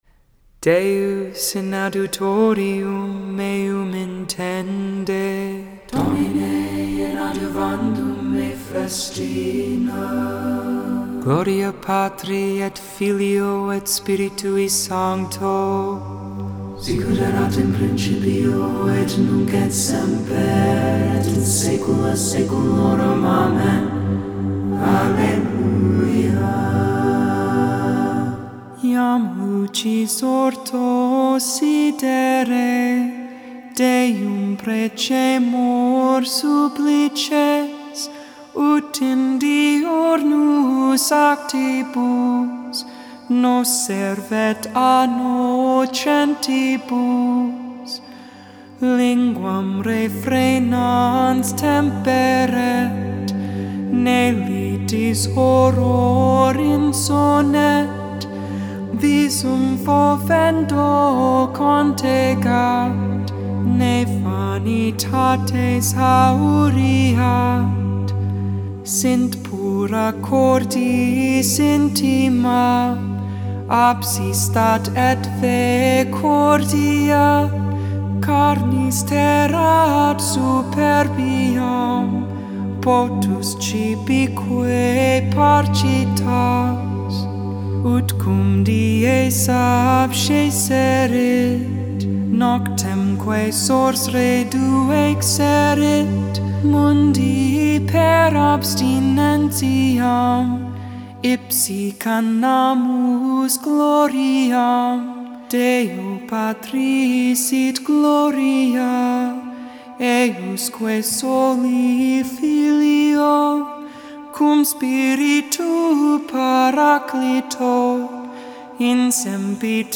Lauds (Morning Prayer) for the 8th Thursday in Ordinary Time, May 27th, 2021.